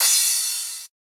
cymbal.ogg